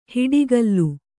♪ hiḍigallu